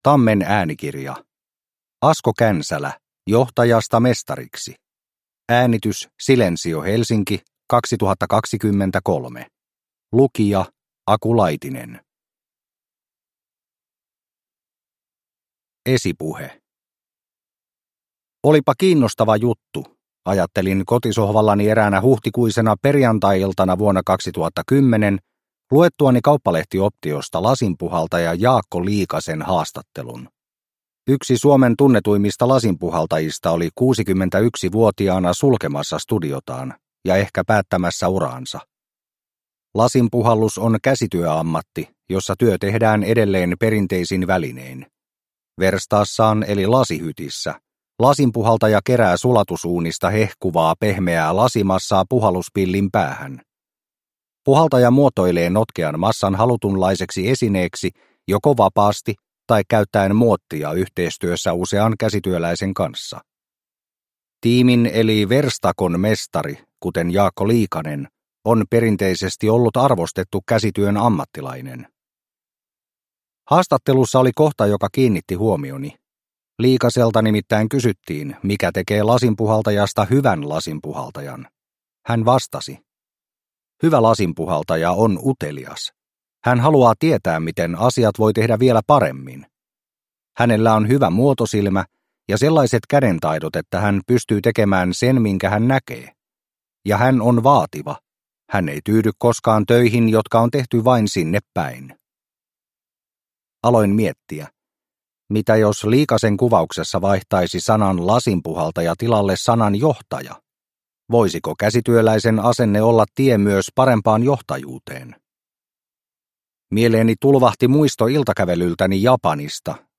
Johtajasta mestariksi – Ljudbok – Laddas ner